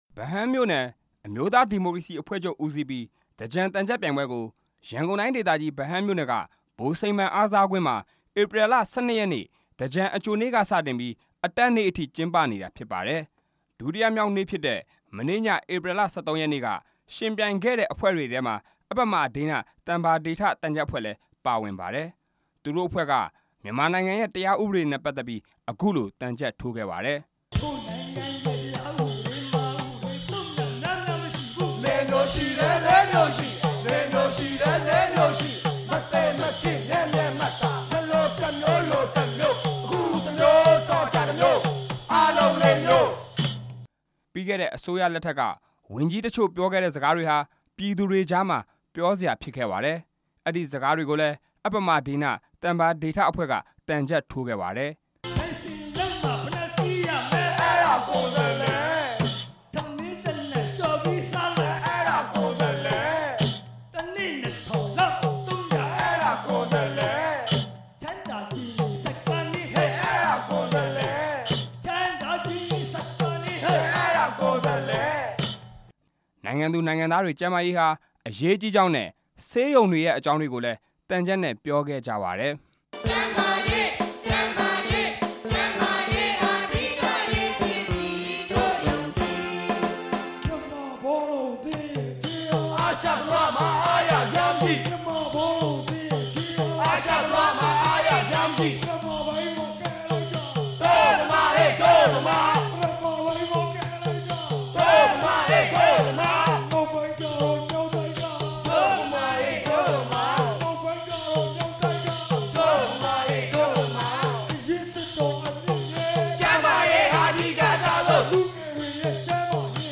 ရန်ကုန်တိုင်းဒေသကြီး ဗဟန်းမြို့နယ် ဗိုလ်စိန်မှန်အားကစားကွင်းမှာ သြင်္ကန်သံချပ်ပြိုင်ပွဲ ကျင်းပနေတာ မနေ့ညက ဒုတိယမြောက်နေ့ဖြစ်ပါတယ်။